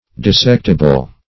Search Result for " dissectible" : The Collaborative International Dictionary of English v.0.48: Dissectible \Dis*sect"i*ble\, a. Capable of being dissected, or separated by dissection.